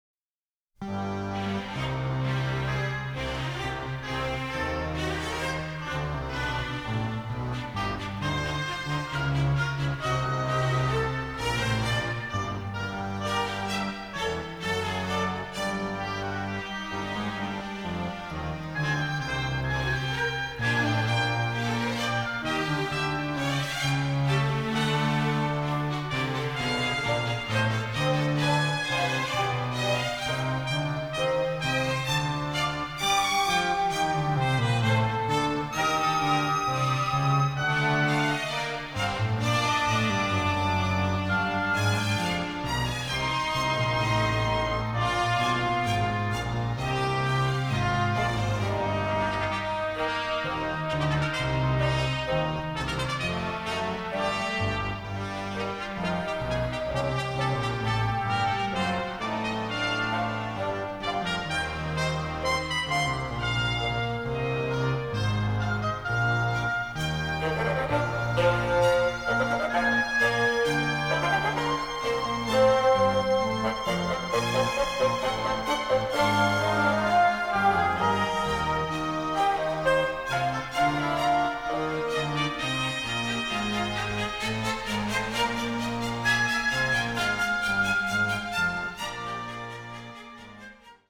bizarre sci-fi score
electronic music